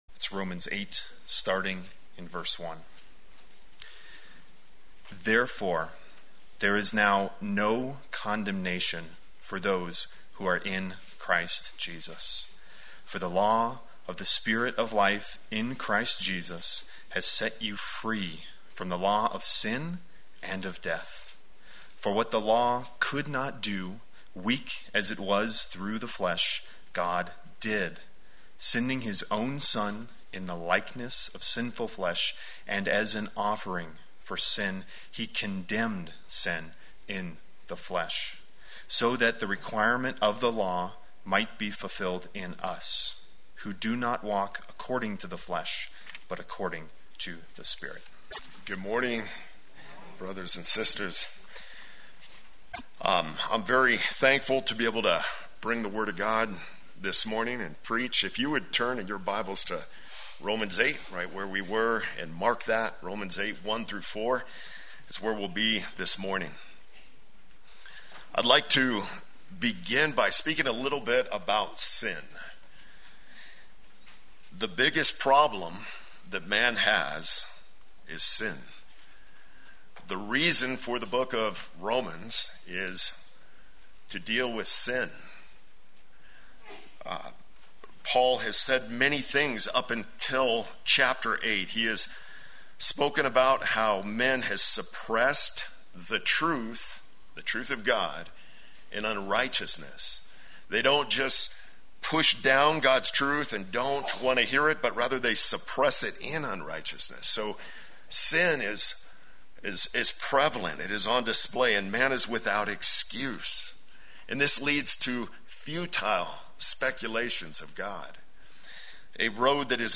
Play Sermon Get HCF Teaching Automatically.
No Condemnation for the Christian Sunday Worship